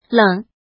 怎么读
lěng
leng3.mp3